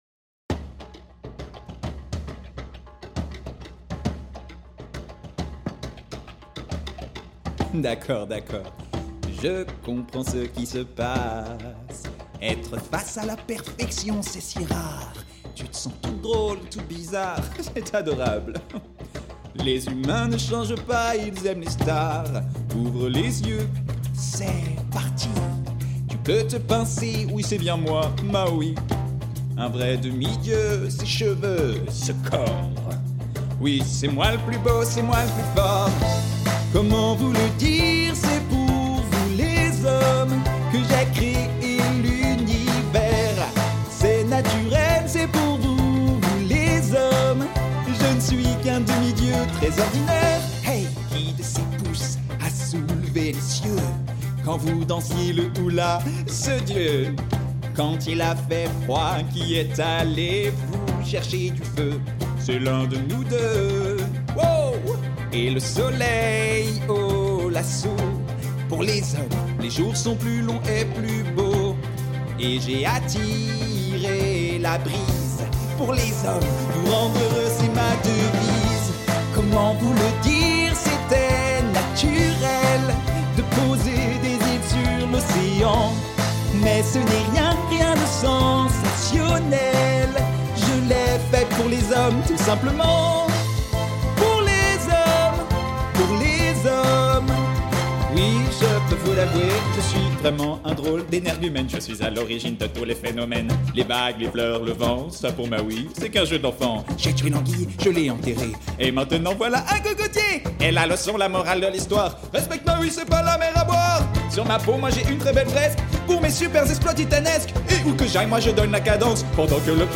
- Baryton